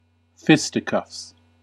Ääntäminen
Synonyymit (arkikielessä) fight (arkikielessä) brawl (arkikielessä) fist-fight (arkikielessä) punch-up prizefighting scrape boxing fistfight Ääntäminen US UK : IPA : /ˈfɪs.tɪ.kʌfs/ US : IPA : /ˈfɪs.tɪ.kʌfs/